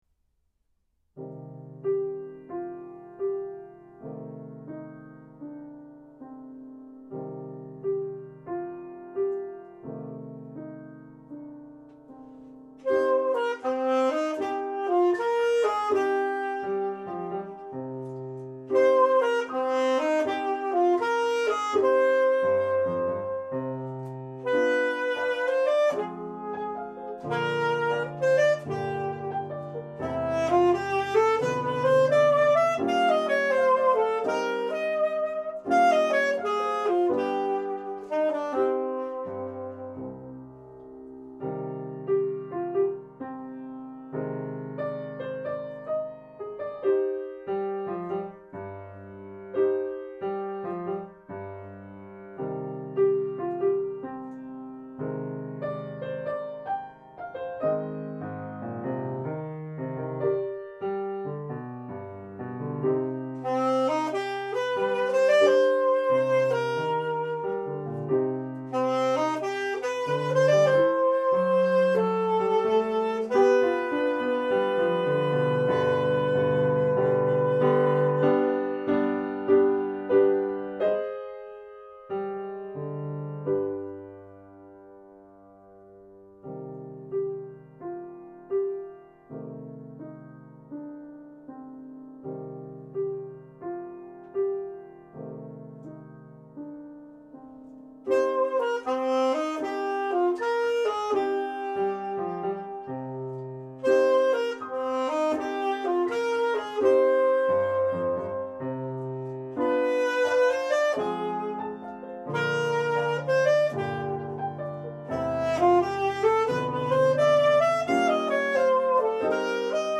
alto saxophone
alto saxophone and piano
A jazzy piece